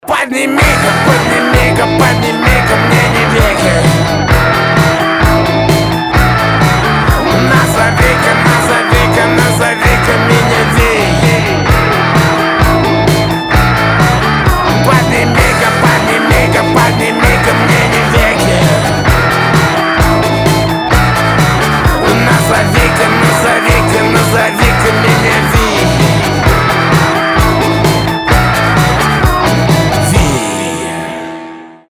• Качество: 320, Stereo
забавные
веселые
саундтреки
Забавный саундтрек